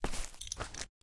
sounds / material / human / step / earth3.ogg
earth3.ogg